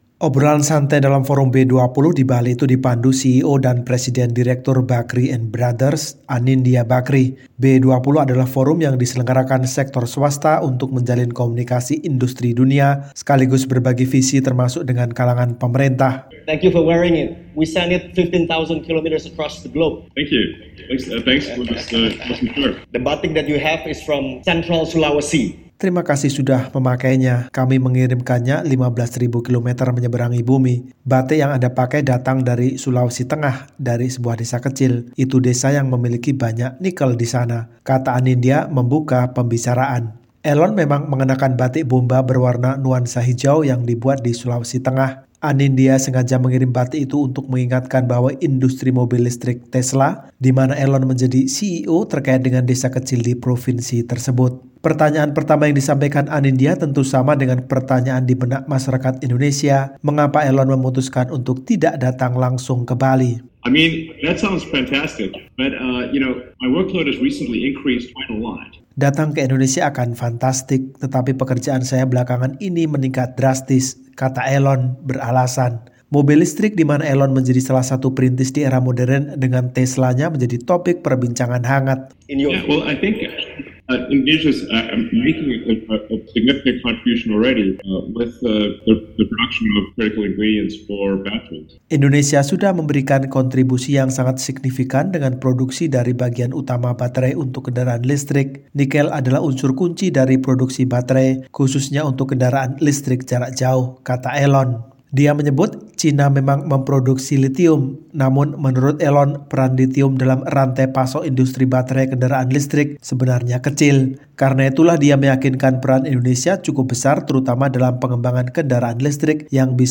Meski digadang-gadang untuk hadir langsung ke Bali, Elon Musk pada akhirnya memilih untuk berpartisipasi di forum B20, yang merupakan rangkaian KTT G20, secara daring.